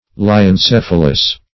Search Result for " lyencephalous" : The Collaborative International Dictionary of English v.0.48: Lyencephalous \Ly`en*ceph"a*lous\, a. (Zool.)